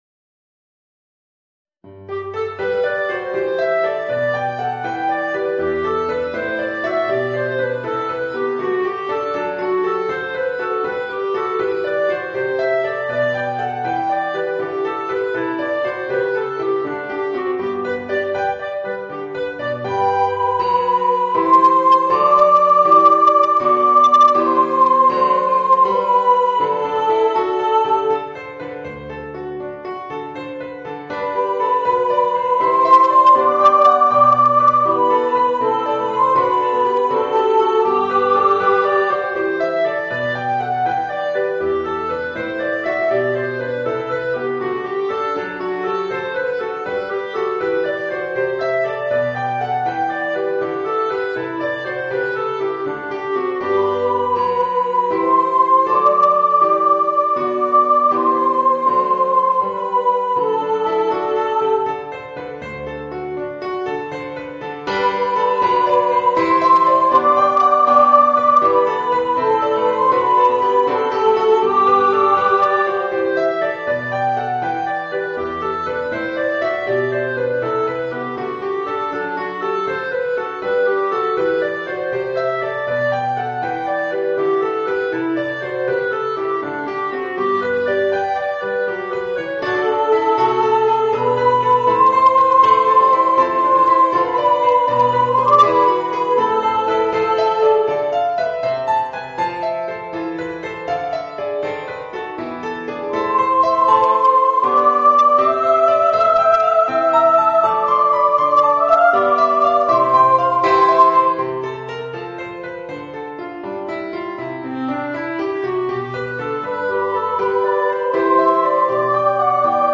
Voicing: Soprano, Clarinet and Piano